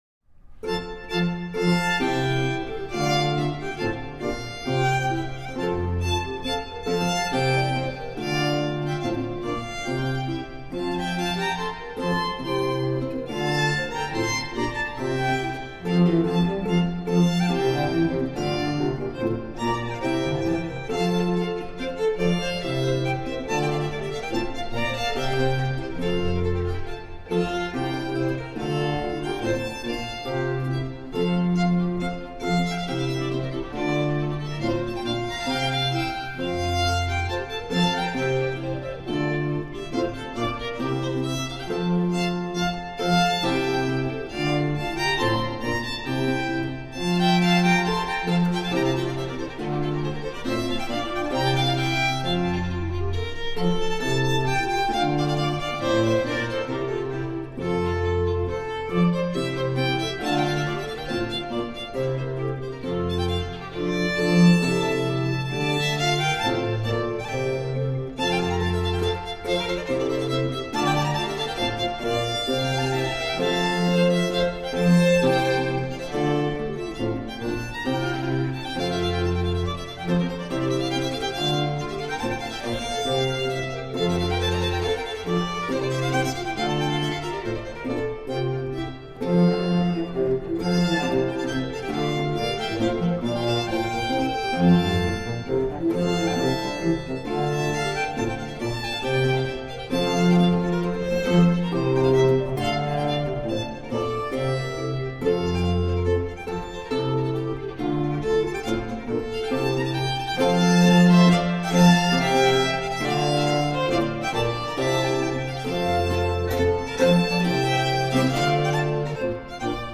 17th century baroque ensemble.
Classical, Orchestral, Baroque